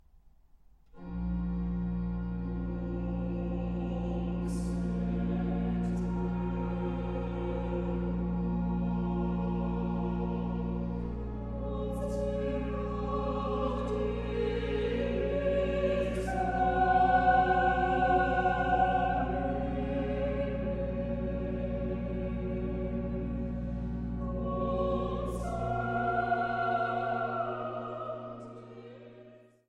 Orgel